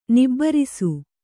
♪ nibbarisu